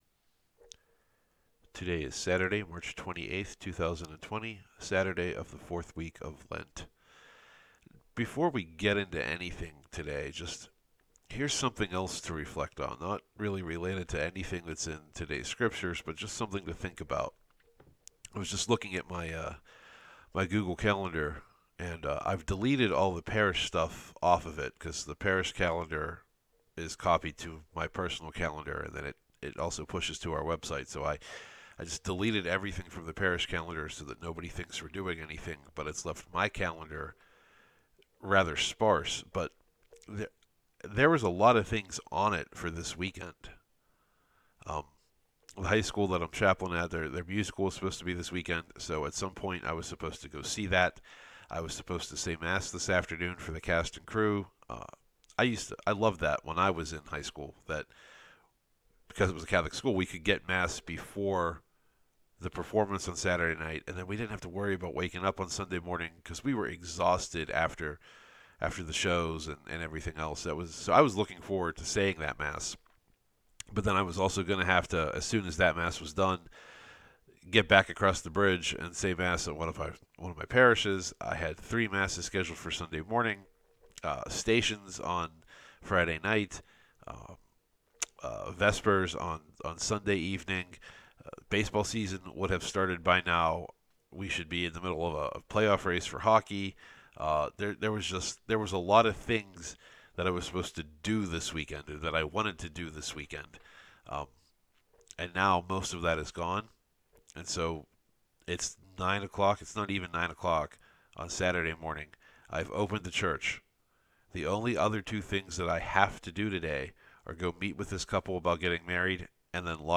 I got this done in one take.